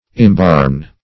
Imbarn \Im*barn"\